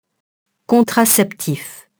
contraceptif, contraceptive [kɔ̃trasɛptif, -iv] adjectif